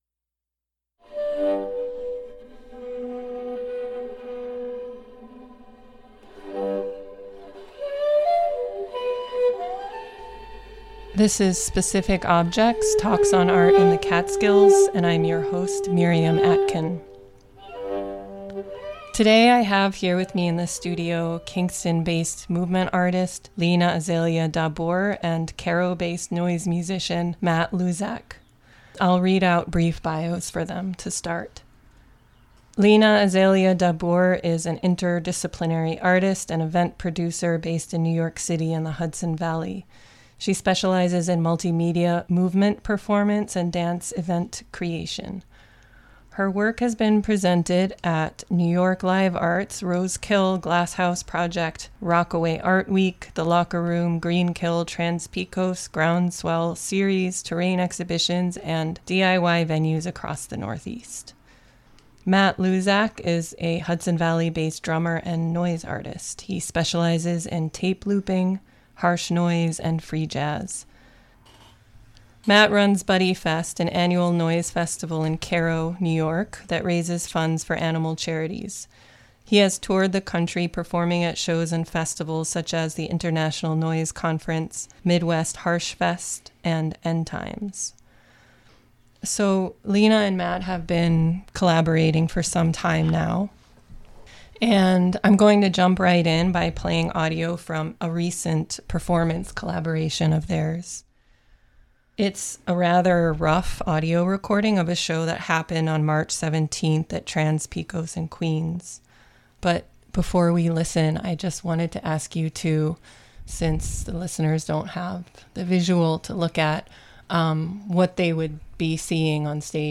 Specific Objects is a monthly freeform discussion